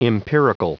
Prononciation du mot empirical en anglais (fichier audio)
Prononciation du mot : empirical